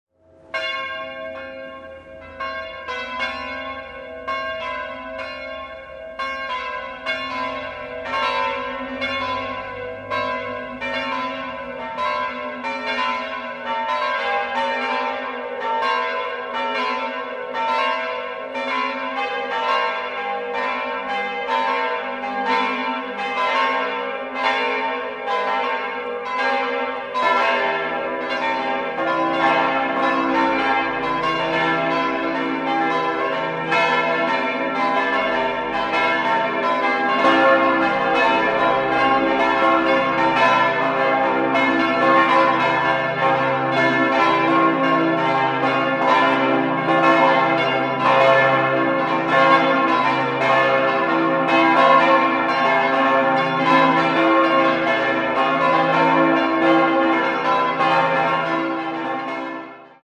6-stimmiges erweitertes Salve-Regina-Geläute: c'-e'-g'-a'-h'-c''
bell
Ein außergewöhnliches Geläute mit einer sehr interessanten und seltenen Disposition. Die Grundglocke erklingt in Dur-Rippe.